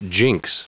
Prononciation du mot jinx en anglais (fichier audio)
Prononciation du mot : jinx